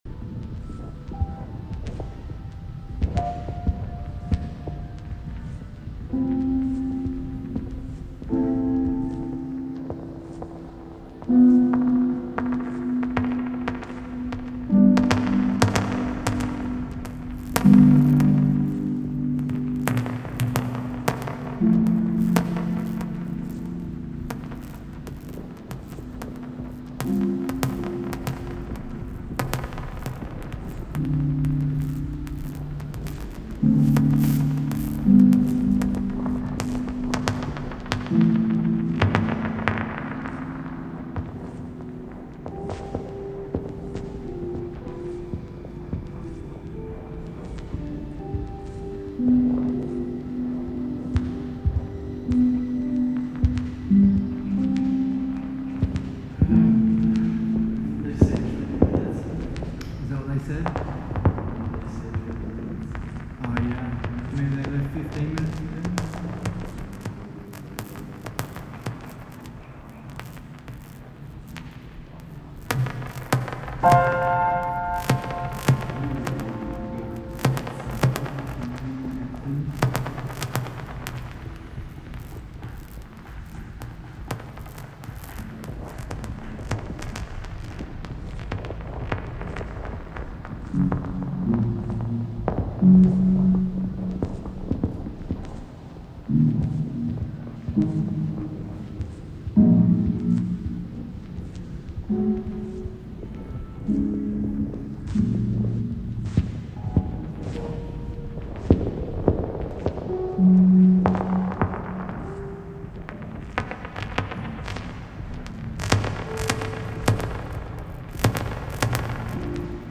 物悲しいピアノとぼんやりとした話し声がクラックルノイズに塗れる